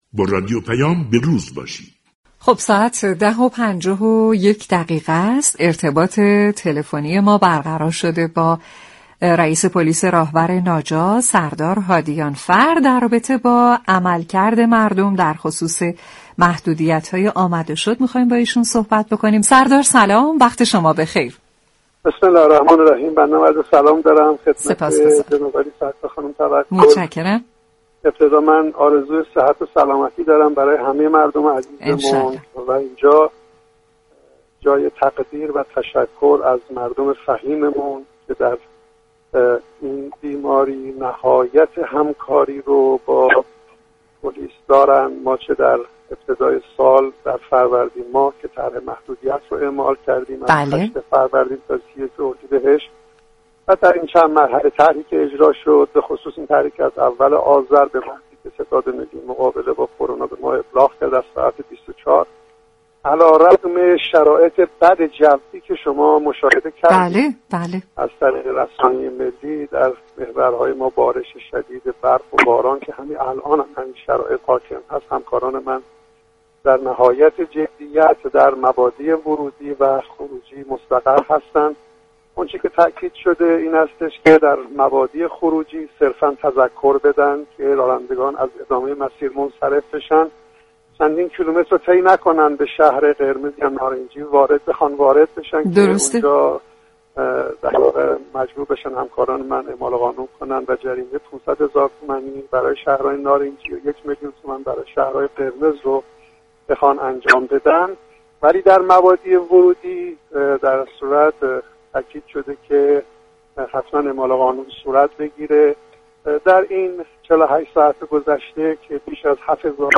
سردار هادیانفر، رئیس پلیس راهور ناجا در گفتگو با رادیوپیام ، آخرین وضعیت ترددها در مسیرهای مواصلاتی كشور در زمان محدودیت های كرونایی را بازگو كرد